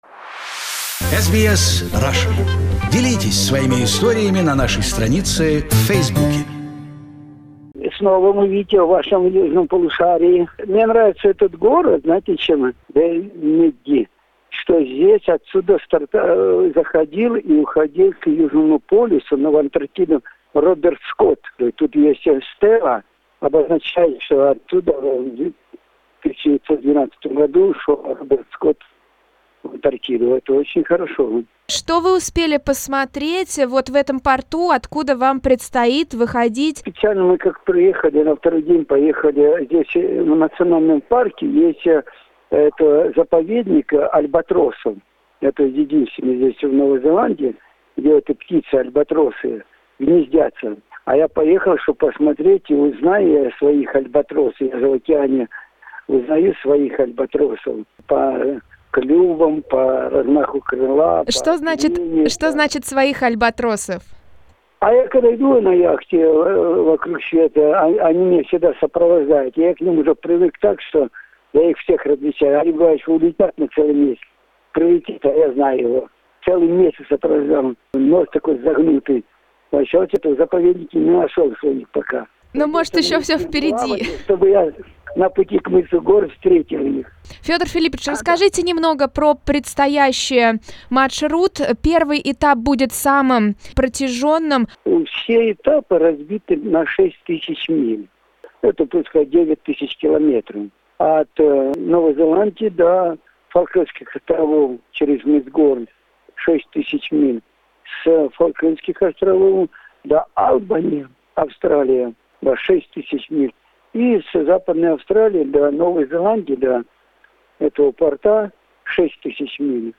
Перед началом маршрута, который будет проходить в суровых условиях южных широт, мы созвонились с Федором и поговорили про его страсть к путешествиям, которая часто толкает его на опасности.